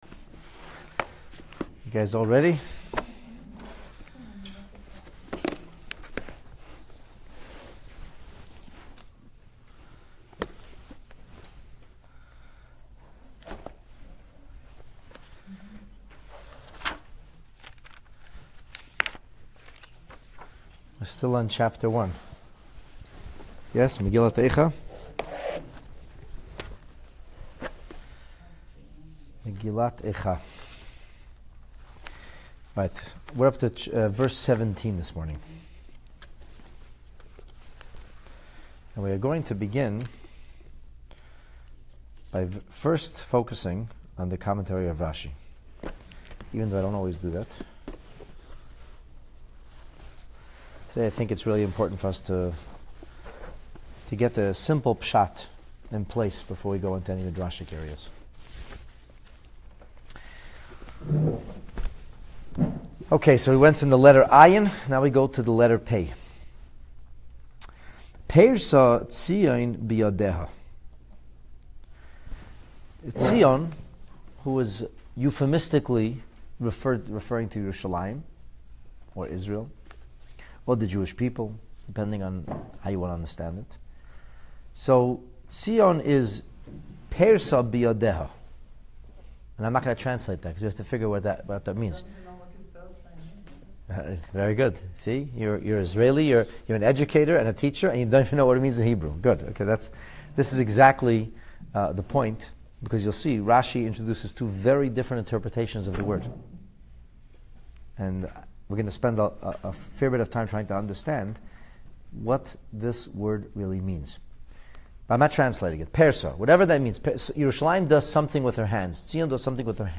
This class poignantly illustrates how this tragic poetry is textbook description of our Exilic Experience.